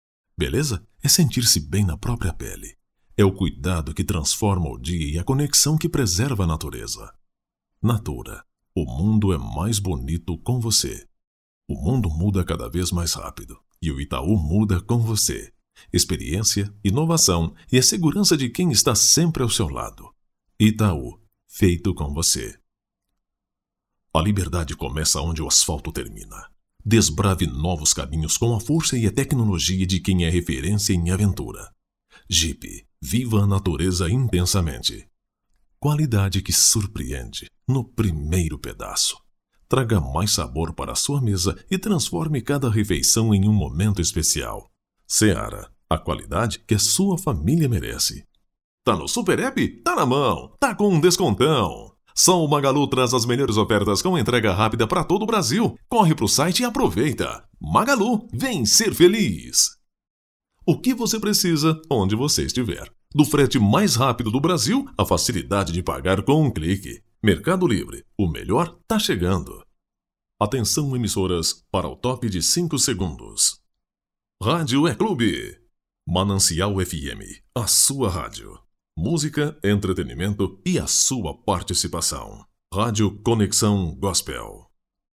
Spot Comercial
Vinhetas
Impacto
Animada